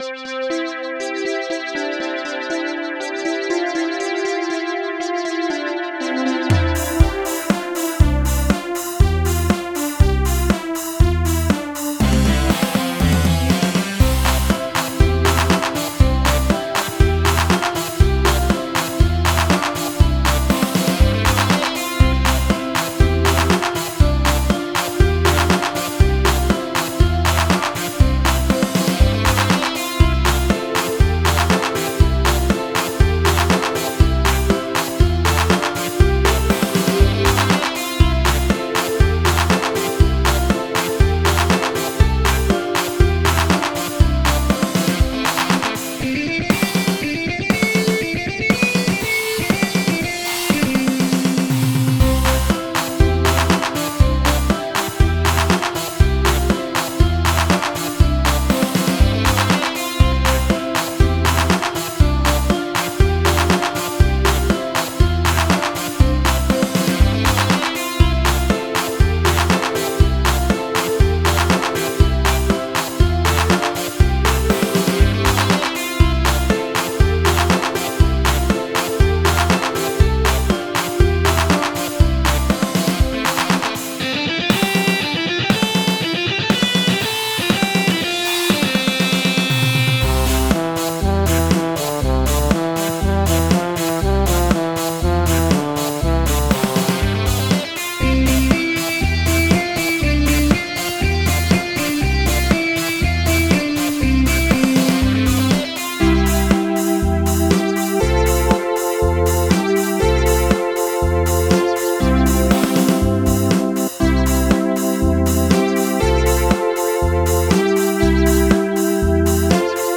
с помощью компьютера и синтезатора
Инструментальная версия